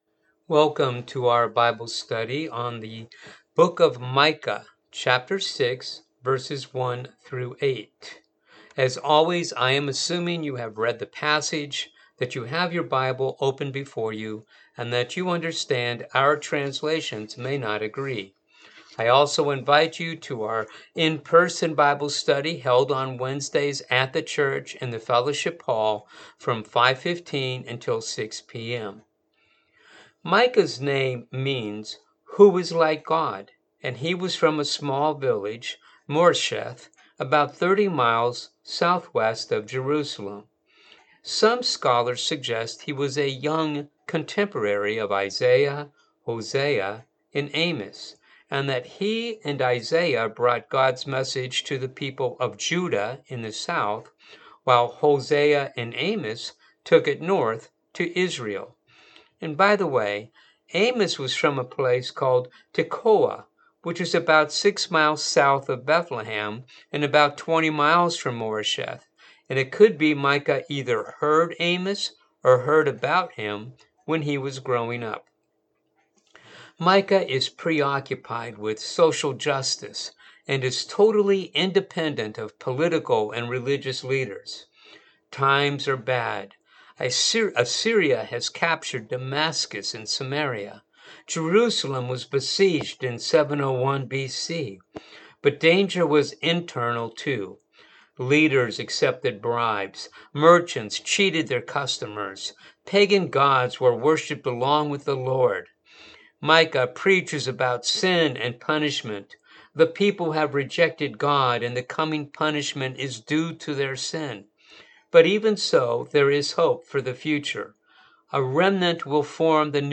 Bible Study for the January 29 Service